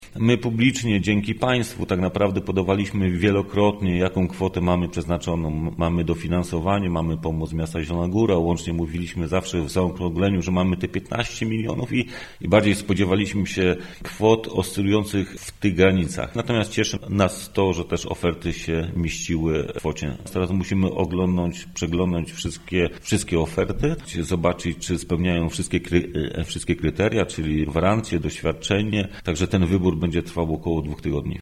Mirosław Andrasiak, członek zarządu powiatu zauważa, że rozpiętość cenowa może zaskakiwać: